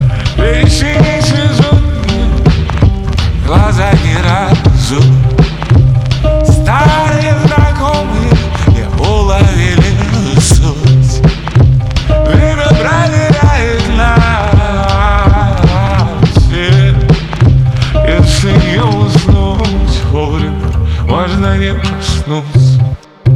русский рэп , грустные
спокойные